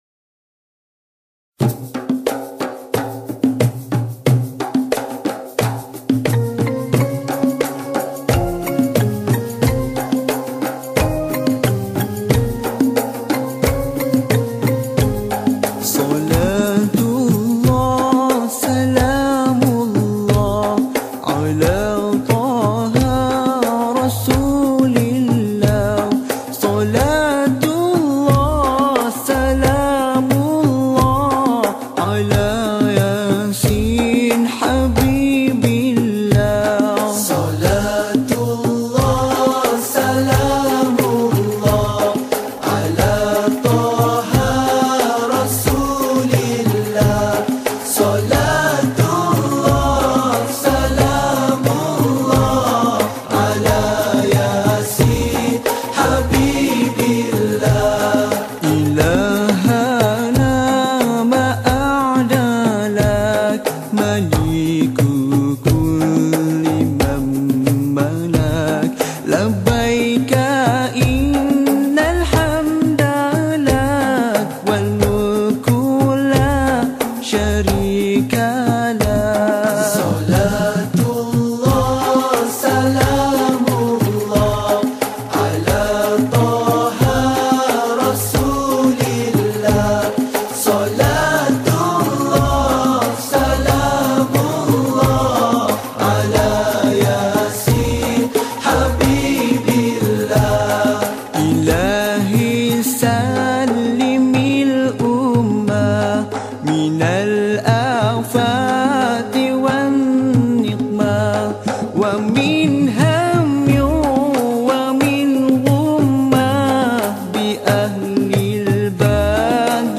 Malay Sufi Music